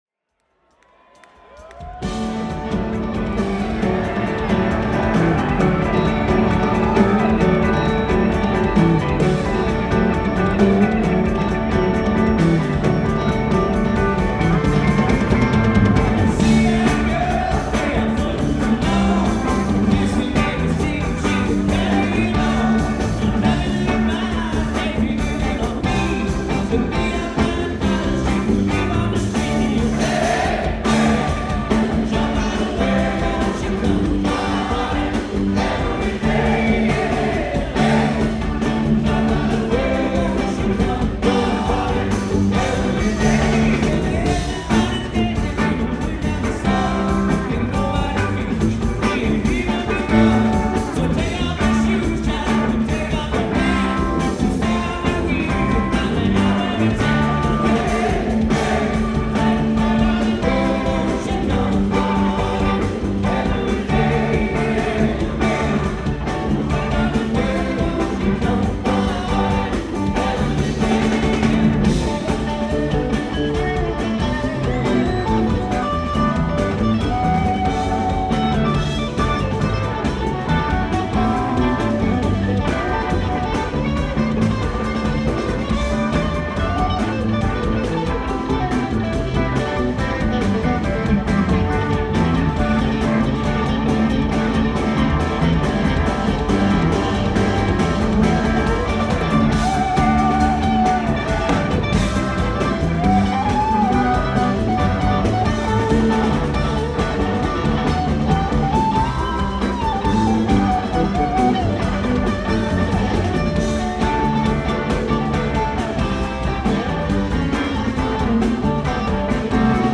New Year's Eve @ The Cap!
bass
drums
guitar
keys